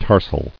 [tar·sal]